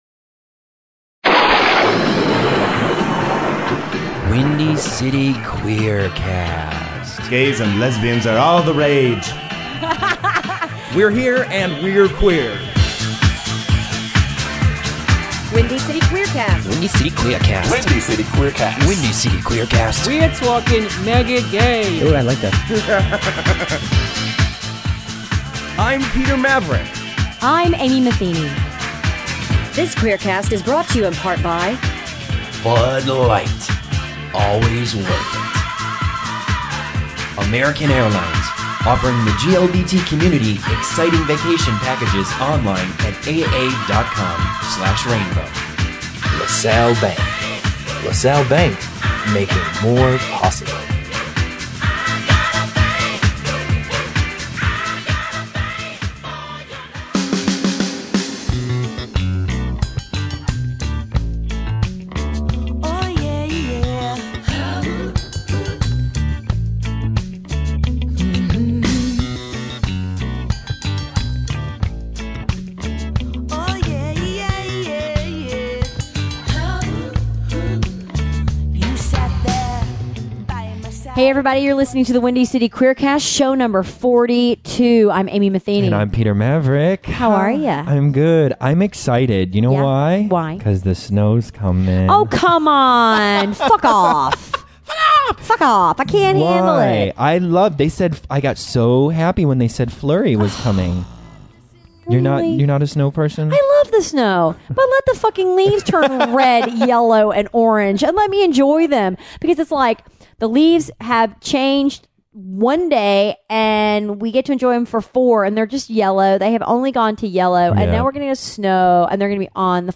Guest: Stage and screen actress and singer Daphne Rubin-Vega...